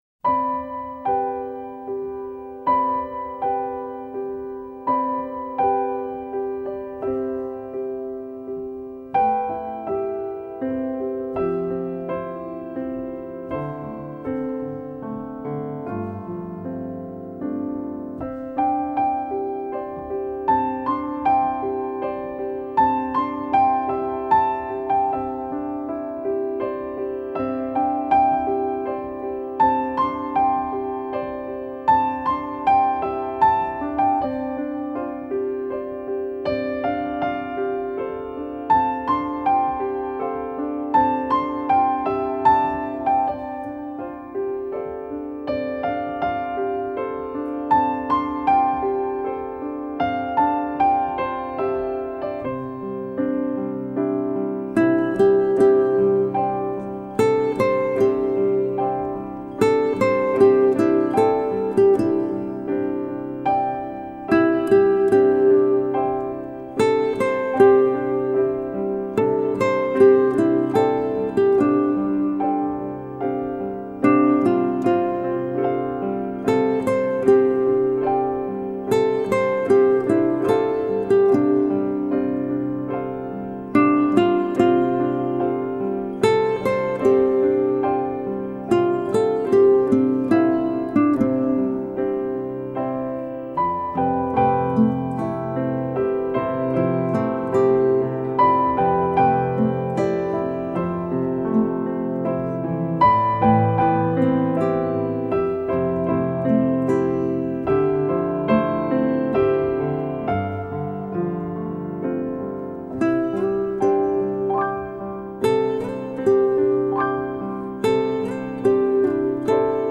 音乐类型：新世纪乐